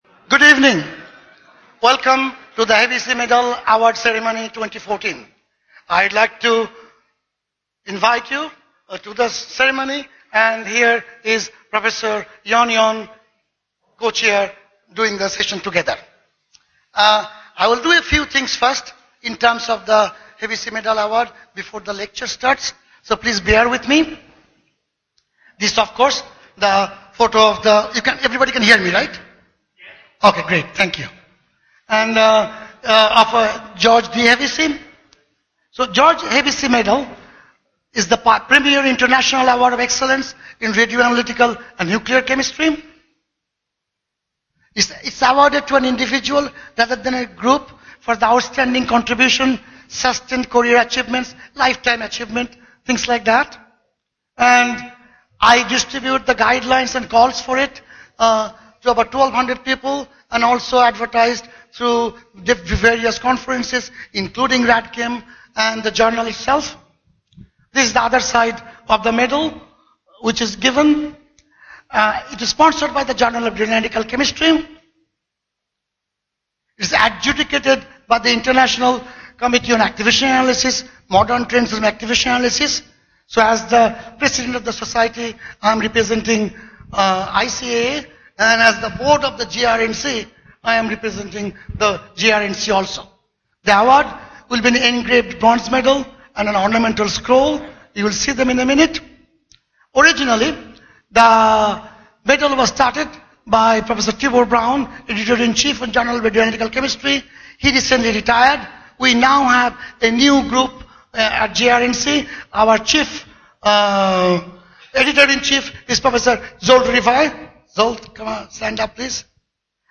hevesyavardlecture.mp3